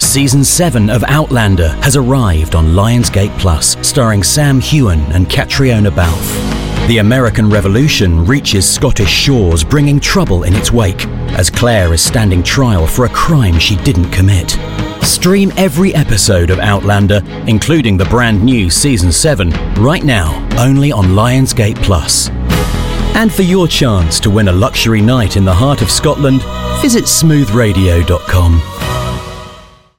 40's Neutral/RP,
Friendly/Confident/Natural
Commercial Showreel